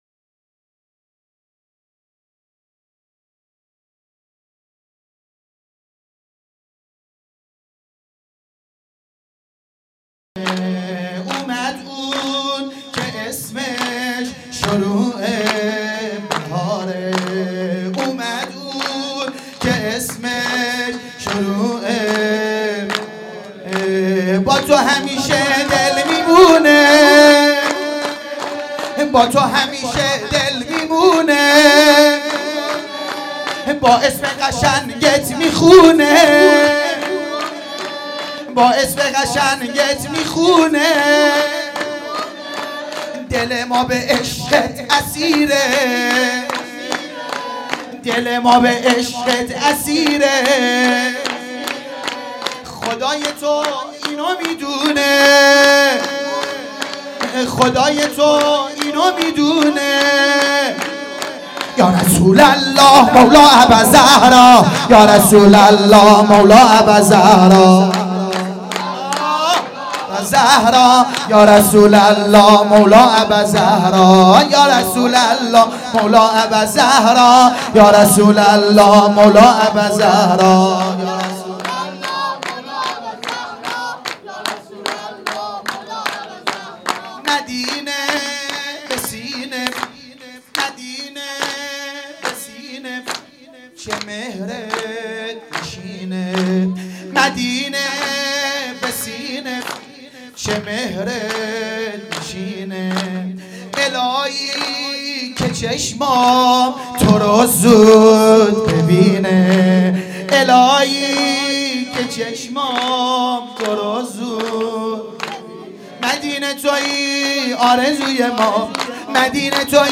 مولودی خوانی | اومد اون که اسمش شروع بهاره
مولودی خوانی در ولادت پیامبر اکرم(ص) و امام جعفر صادق(ع) | هیأت شهدای گمنام شهرستان صومعه سرا